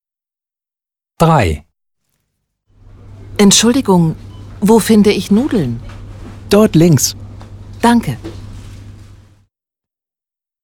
Dialog 3: